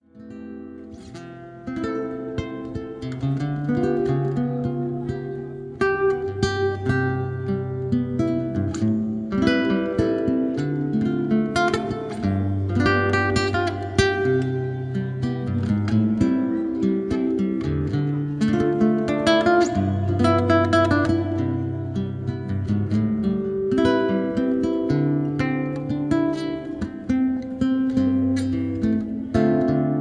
Solo Guitar Standards
Live in Little Tokyo
Soothing and Relaxing Guitar Music